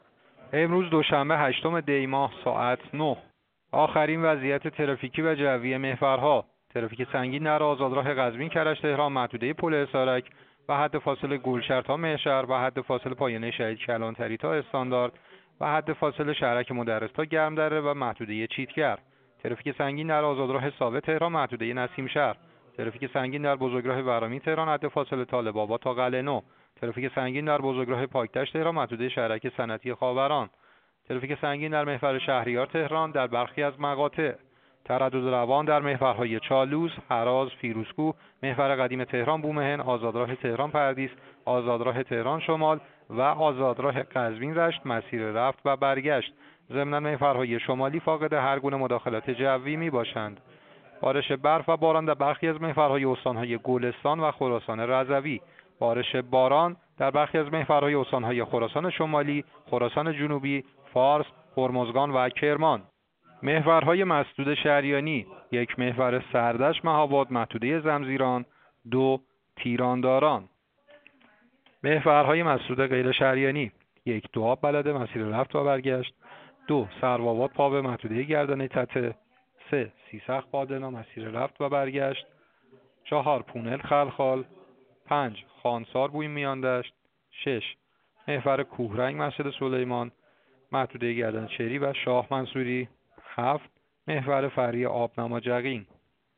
گزارش رادیو اینترنتی از آخرین وضعیت آب و هوای هشتم دی؛